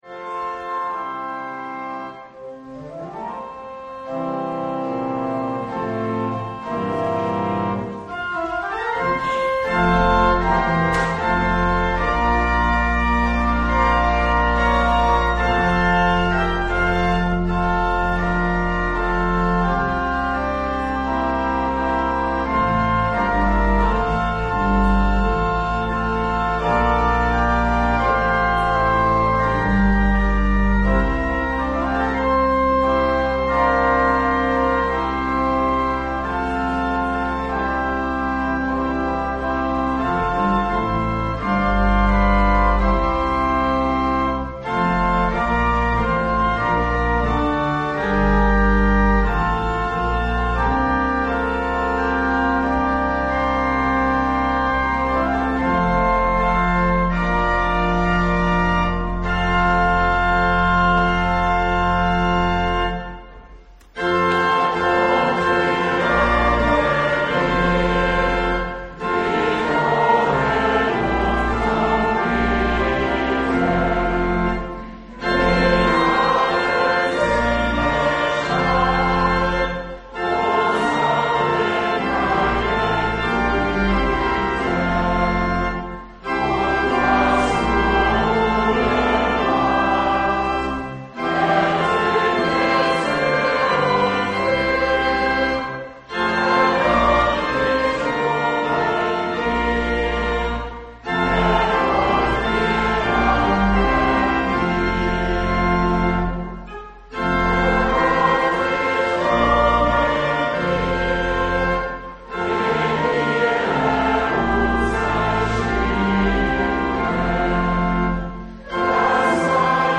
02.07.23 - Predigten 2+3 zu Psalm 92 - Orgelweihe und Tauferinnerung - Kirchgemeinde Pölzig